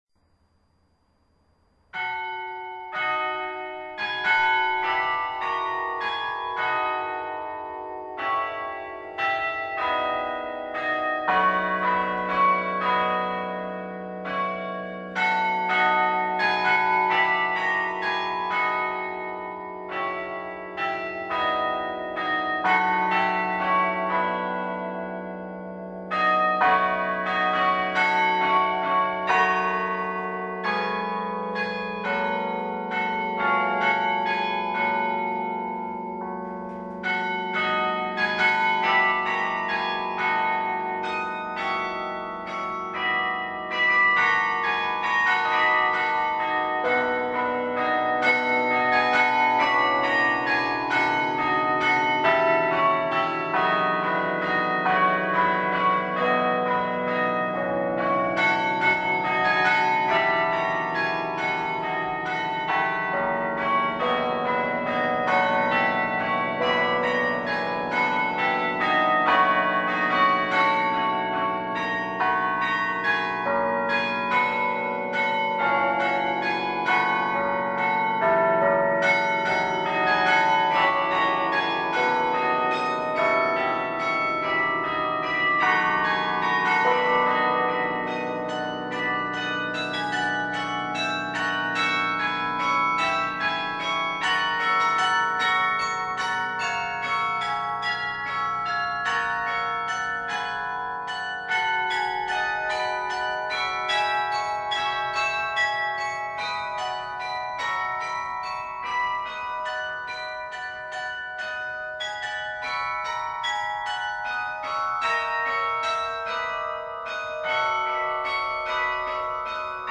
Fleischmann Carillon Recital 13 June 2010 in St Colman’s Cathedral, Cobh
A German Folk Song: ‘Die Lorelei’